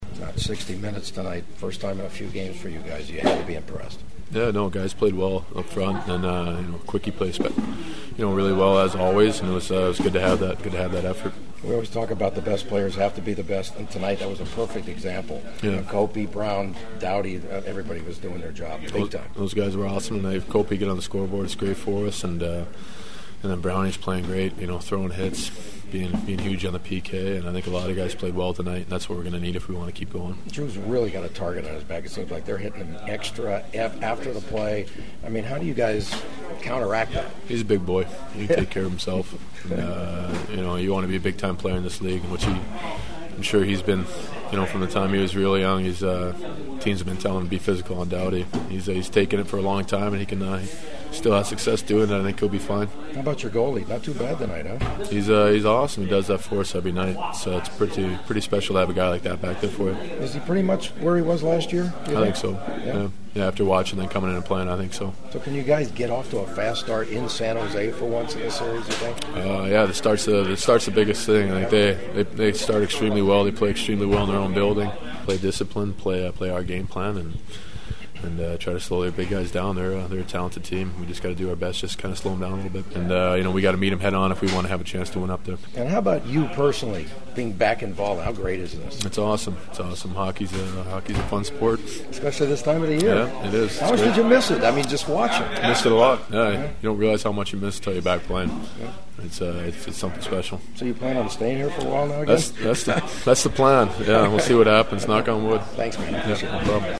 The sounds of the postgame are ahead from a confident Kings locker room:
Defenseman Matt Greene: